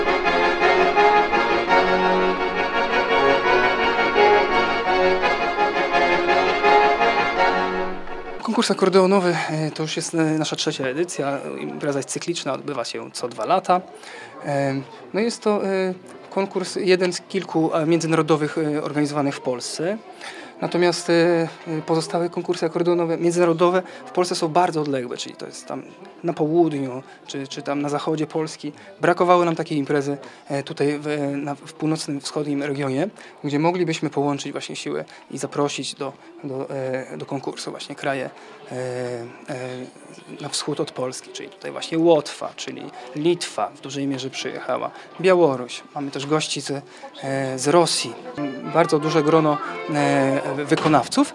Ponad 150 młodych akordeonistów uczestniczyło w piątek (20.04) w III Międzynarodowym Konkursie Akordeonowym w Suwałkach.